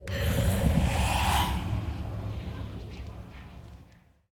ambient_ominous4.ogg